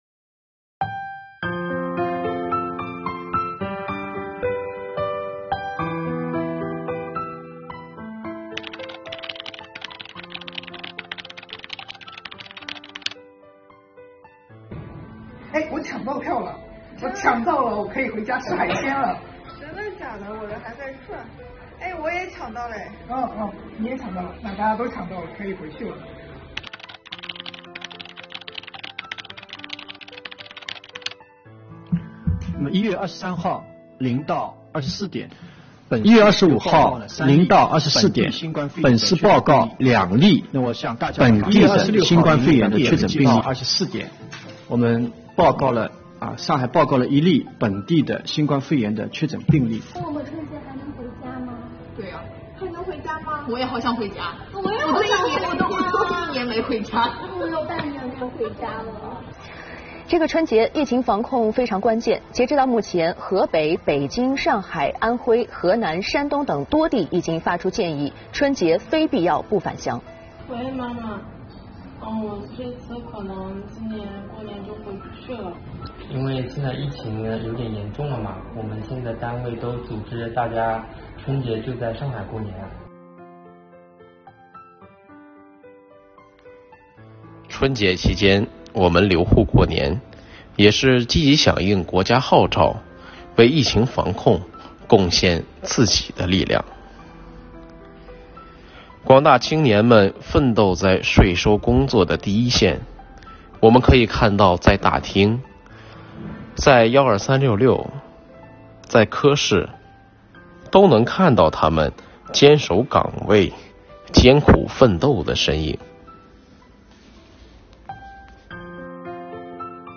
请看我们自编自导自演的微电影，
29位留沪青年用心演绎，真情流露。